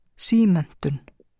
framburður
sí-menntun